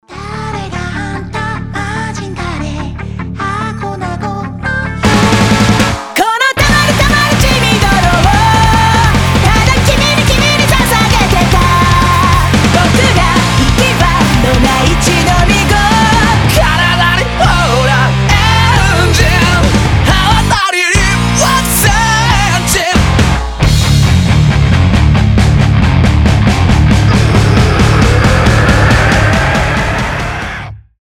j-rock , метал